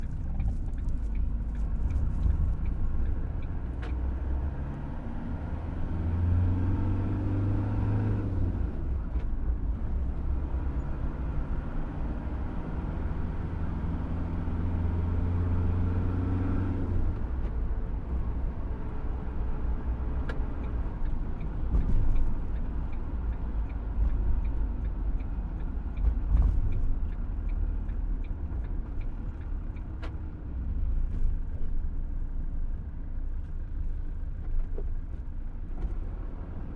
校车" 校车卡车在高速公路上行驶时，在颠簸中减速空转
描述：校车卡车int驾驶高速公路减速到空闲的bumps.flac
Tag: 公路 卡车 闲置下来 颠簸 公交车 INT 驾驶 缓慢的 学校